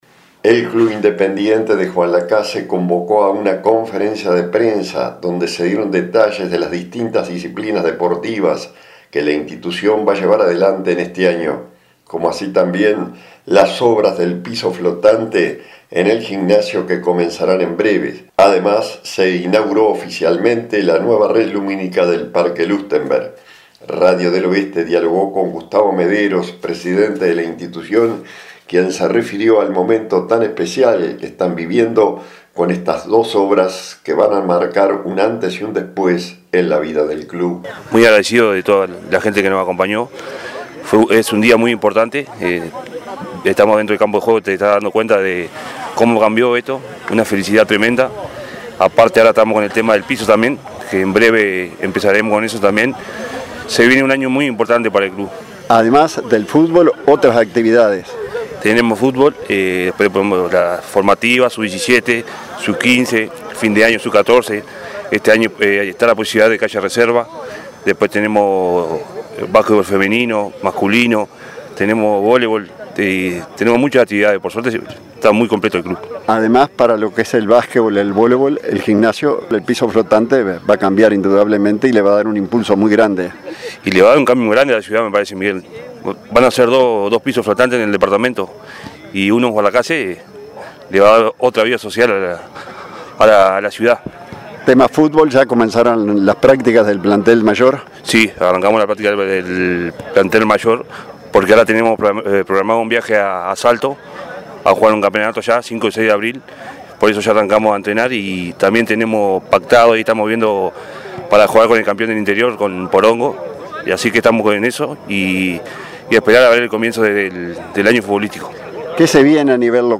Radio del Oeste dialogó con